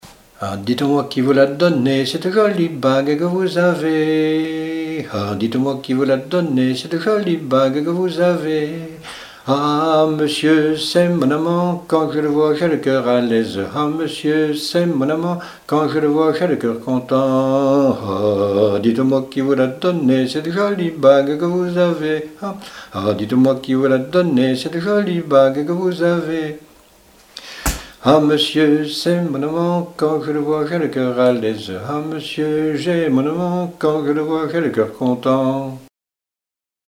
danse : branle : avant-deux
Genre énumérative
Répertoire de chants brefs pour la danse
Pièce musicale inédite